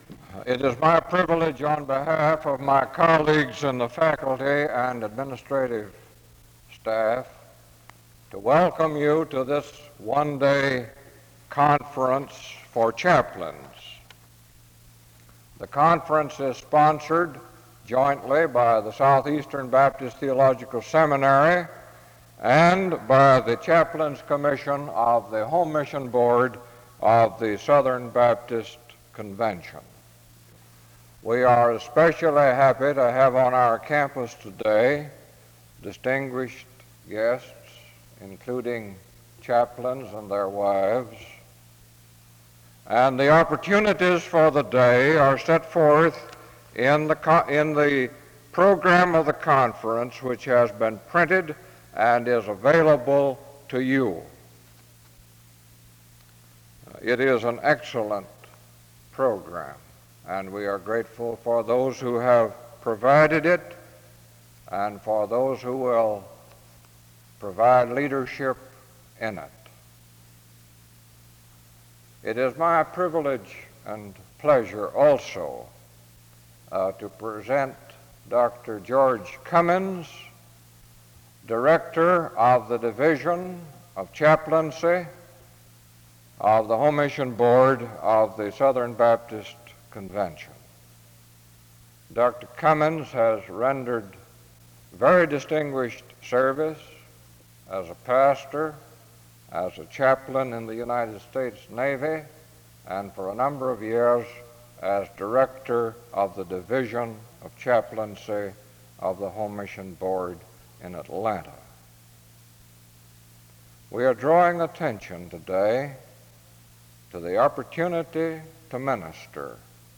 Rev. Brown speaks from 6:29-38:42. Brown preaches on virtues that Christians should exemplify. Closing remarks are given and a prayer is offered from 38:43-40:45.